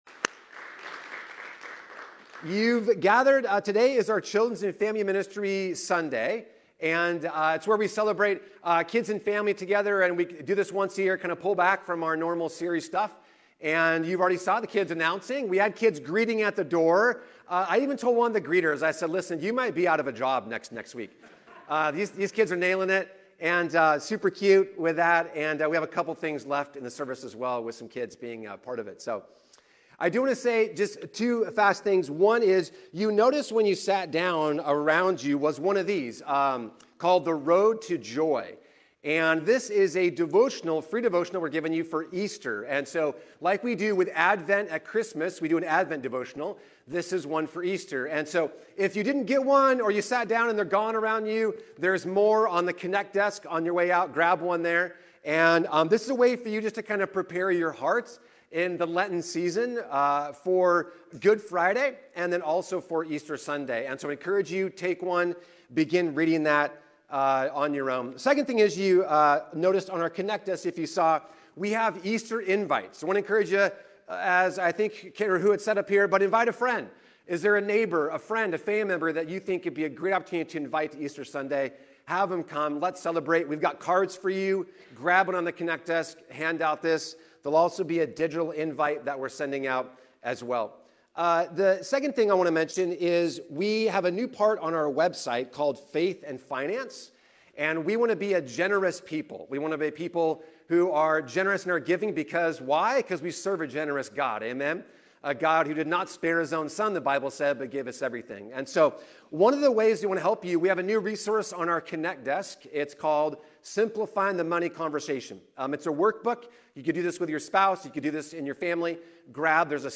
The sermon emphasizes the role of parents in launching their children to live for Jesus.